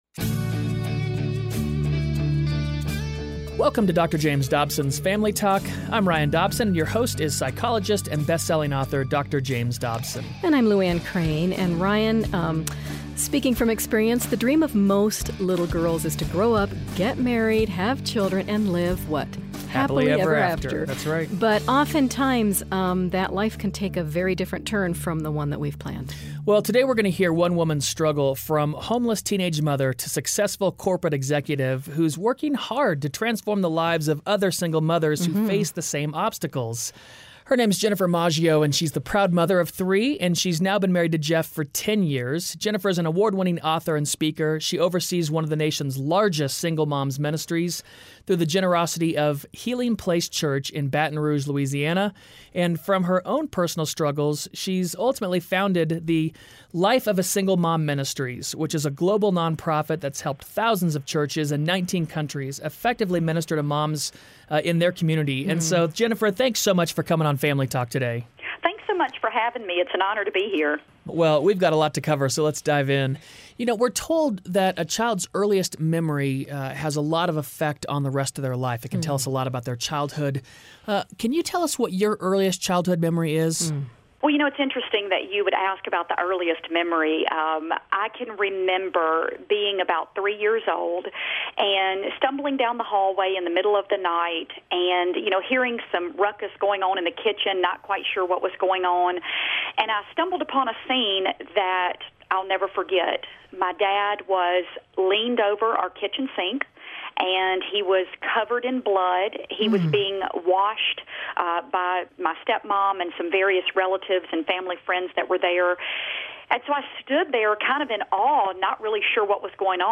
Hear from a woman who struggled with a lonely childhood, abusive relationships, and teenage pregnancies.